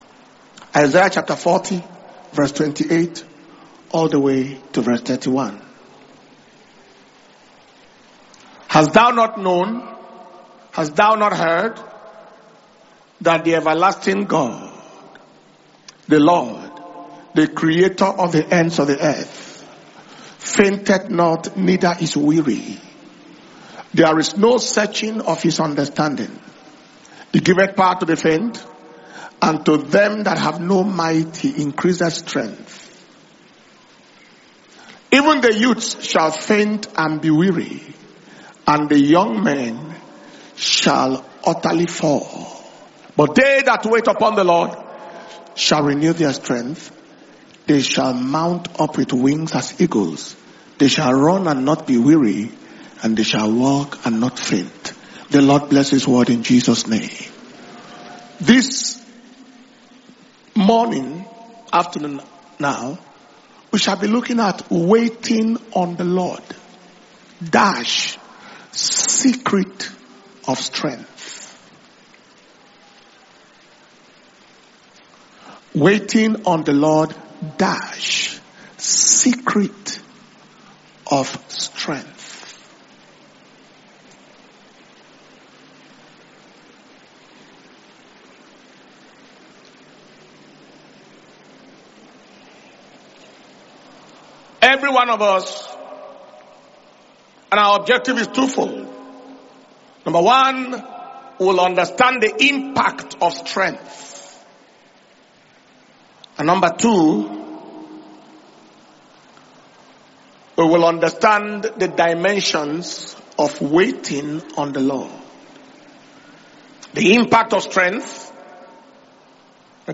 Dunamis Kingdom Power And Glory World Conference 2025 – KPGWC2025